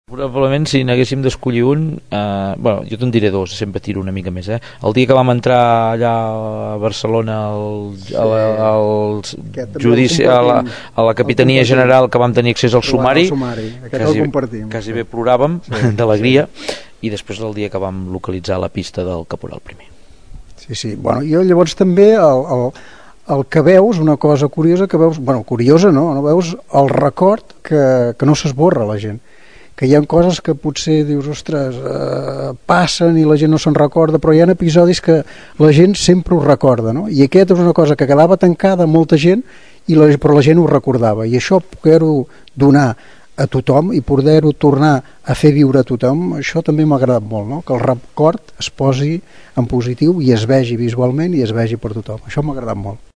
Així ho expliquen als micròfons d’aquesta emissora.